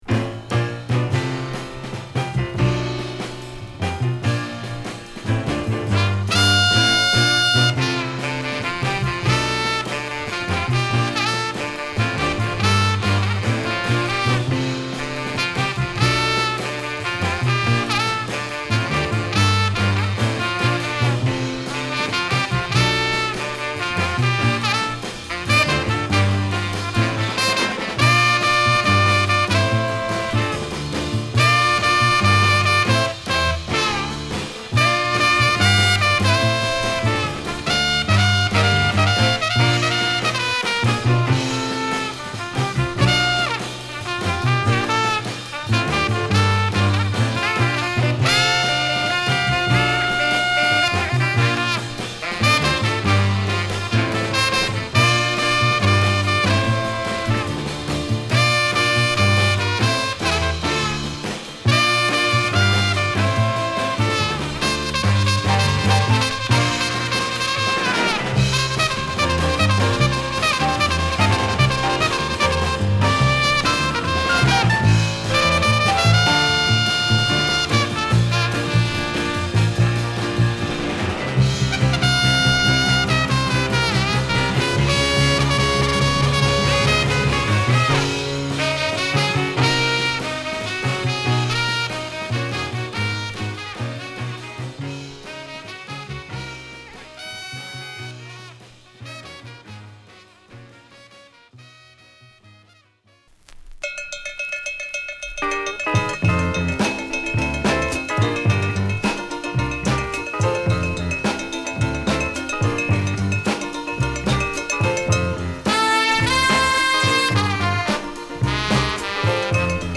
この人の作品はホッコリしてて気持ちいいですねー！